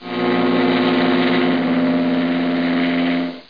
00675_Sound_boat.mp3